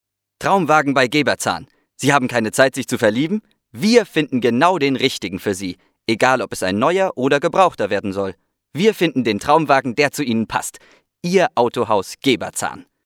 Junge Stimme, derzeit in Schauspielausbildung, für Werbung, Hörspiele und Hörbücher
Kein Dialekt
Sprechprobe: Industrie (Muttersprache):